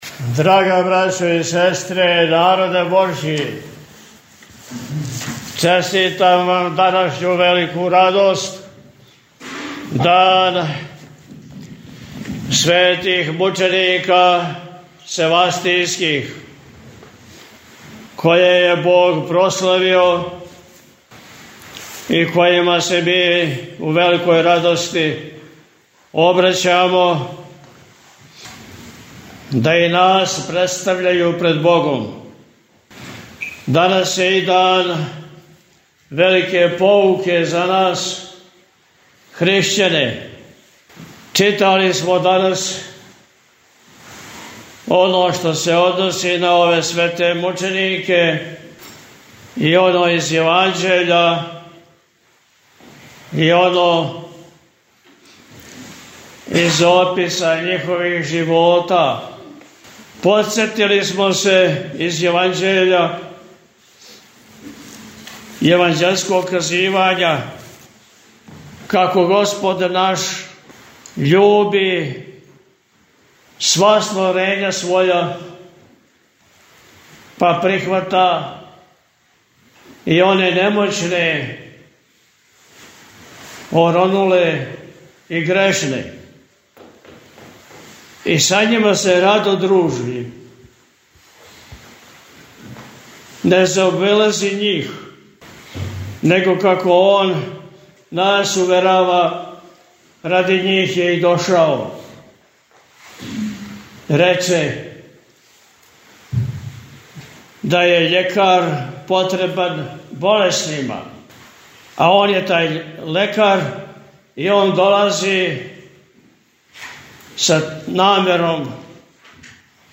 Честитајући празник сабраном верном народу Високопреосвећени је, у пастирској беседи, поред осталог рекао: – Данас је дан велике поуке за нас хришћане.